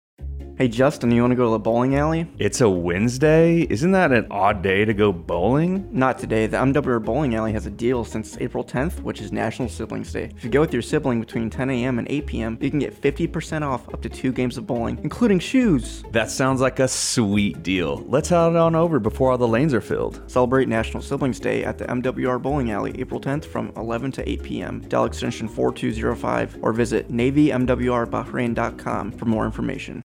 AFN Bahrain Radio Spot - MWR Bowling Alley Specials